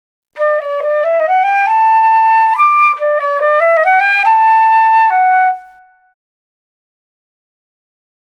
groep6_les1-5-2_blaasinstrumenten9_dwarsfluit
groep6_les1-5-2_blaasinstrumenten9_dwarsfluit.mp3